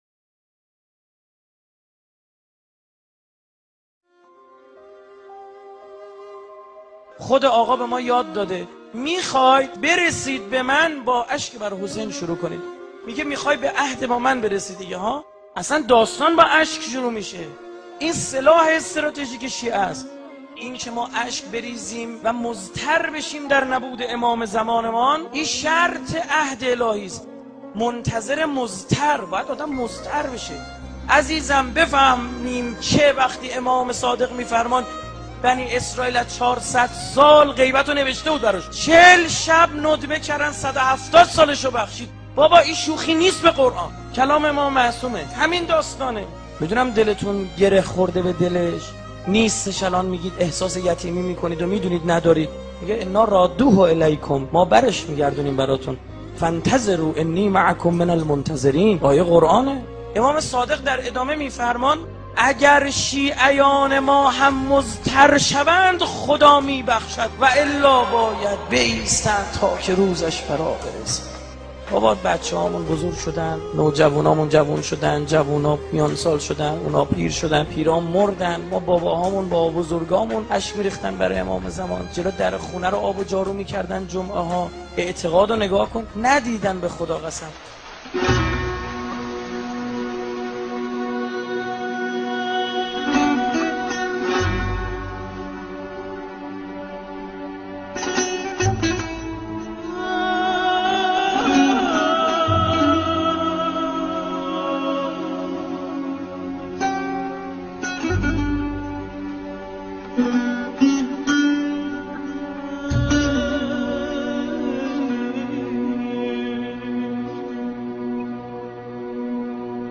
تمامی سخنرانی های : استاد رائفی پور,